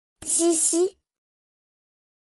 \Zee-Zee\